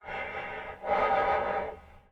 Player_UI [45].wav